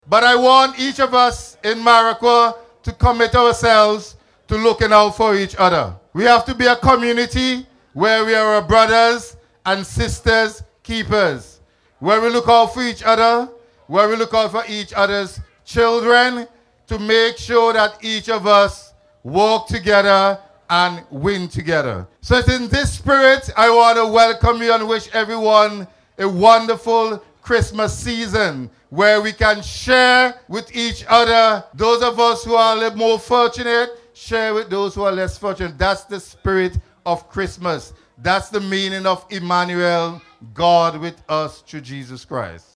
Speaking during the Nine Morning Launch and Light Turning-On Ceremony on Tuesday morning, Jackson highlighted the importance of collective effort and shared responsibility, noting that the festive season is a time to support the less fortunate.